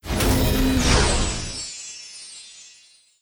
snd_ui_win.wav